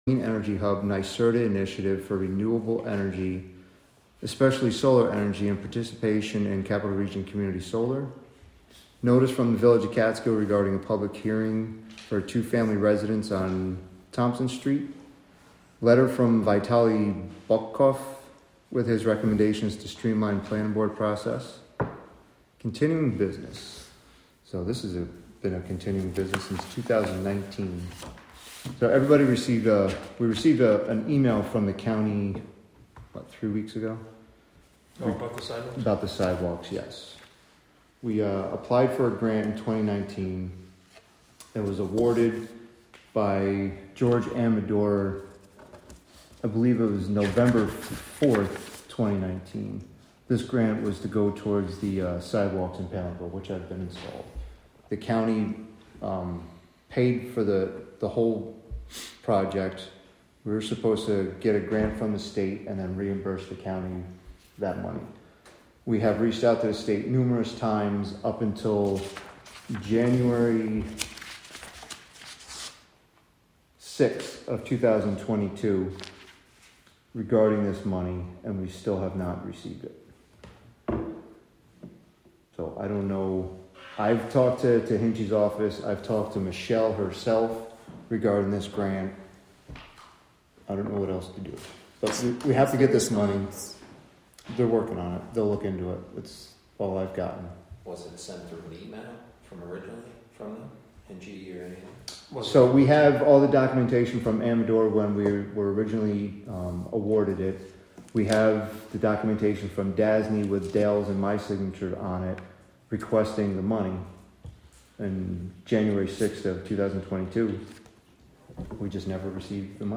Live from the Town of Catskill: April 2, 2024 Catskill Town Board Meeting (Audio)